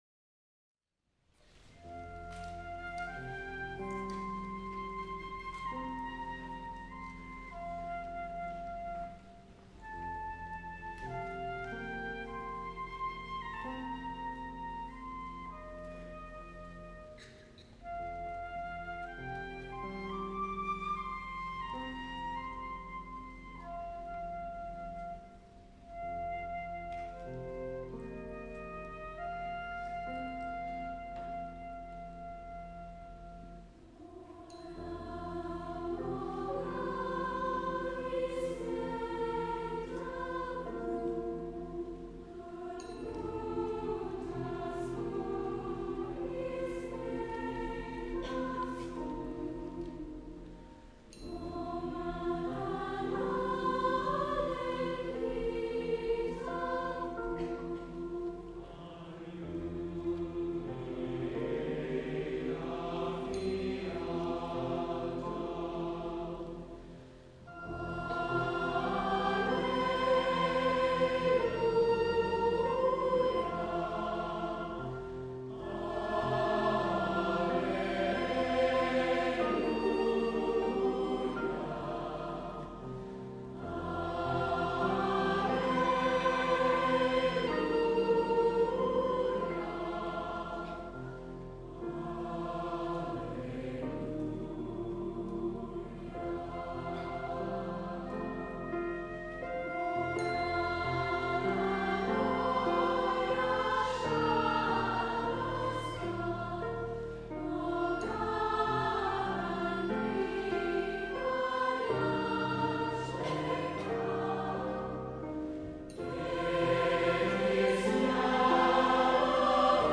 berceuse
SSA (3 voix égale(s) d'enfants OU égales de femmes )
Piano OU Violon
mode de sol